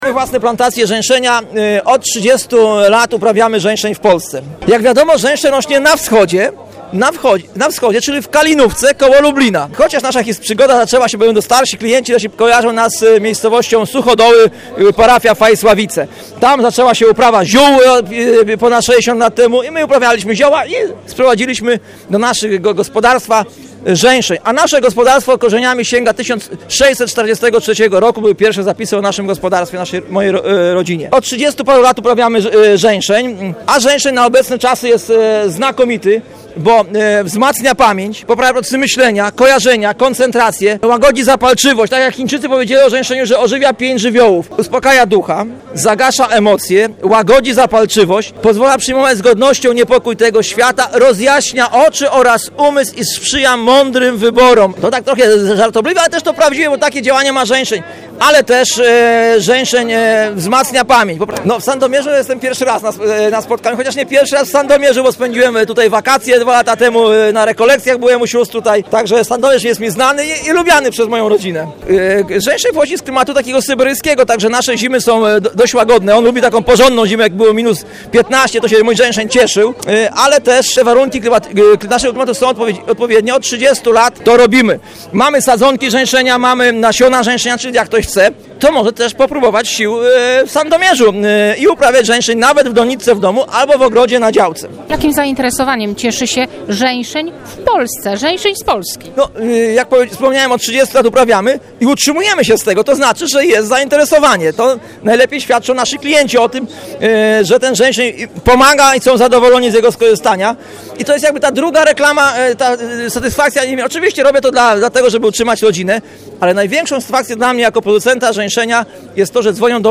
W hali MOSiR w Sandomierzu trwa 32. Spotkanie Sadownicze. Stu wystawców prezentuje sprzęt, środki ochrony roślin oraz akcesoria przydatne w sadzie i polu.